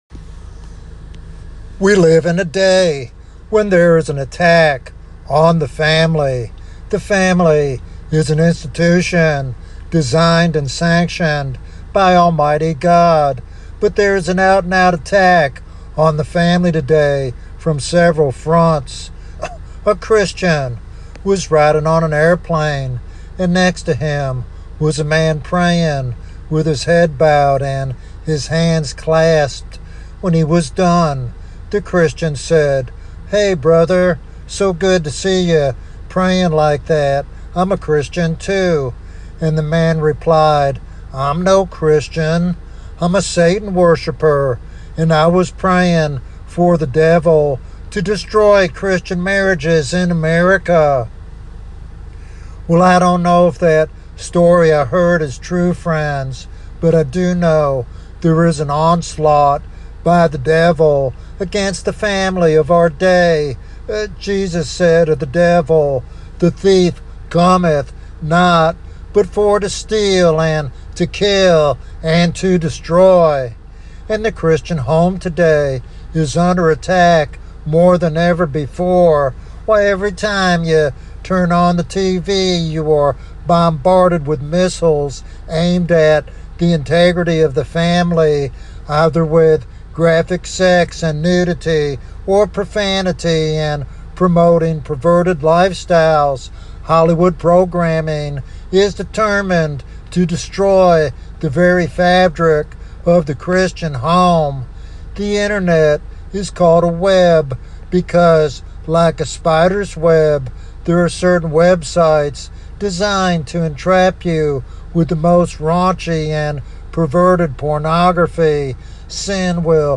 This sermon is a heartfelt call to place Christ at the center of family life for lasting joy and peace.